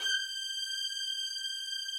strings_079.wav